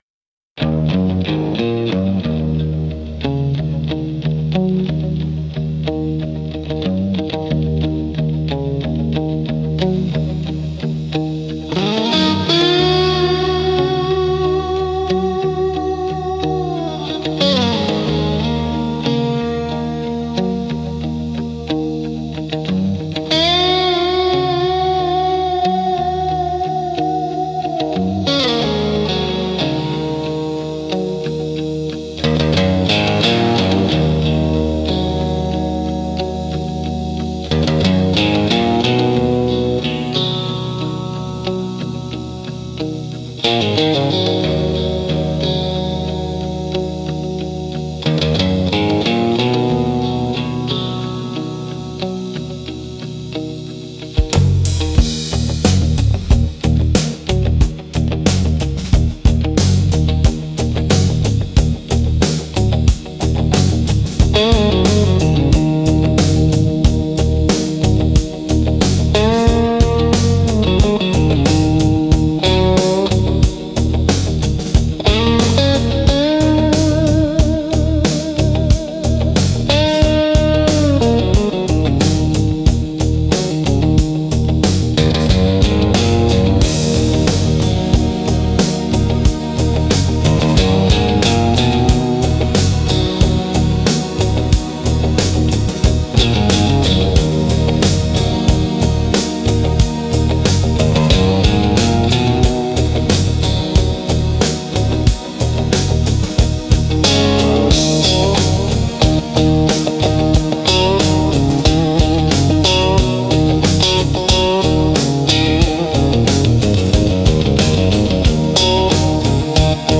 Instrumental - Octaves in the Open 4.19